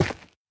Minecraft Version Minecraft Version latest Latest Release | Latest Snapshot latest / assets / minecraft / sounds / block / ancient_debris / break3.ogg Compare With Compare With Latest Release | Latest Snapshot
break3.ogg